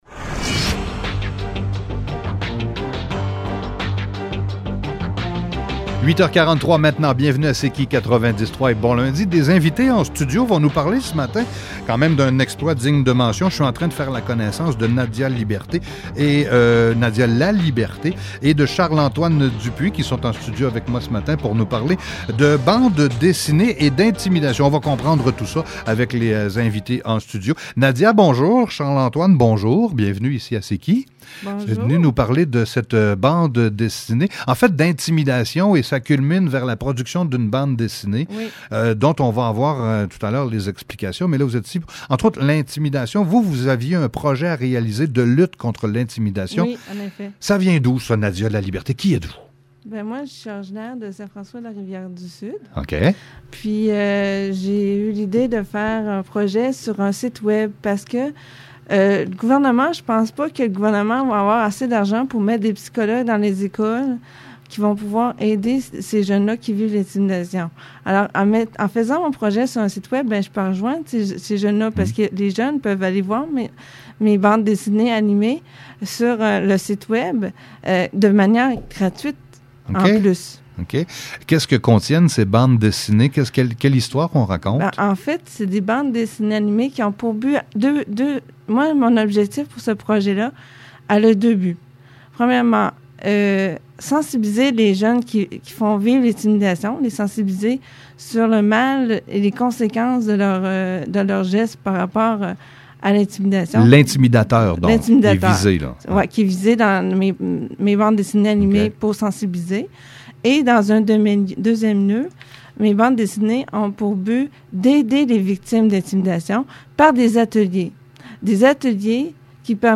Entrevue du 6 juillet 2021 à la radio Ciqi 90,3 fm de Montmagny (début à 8 seconde)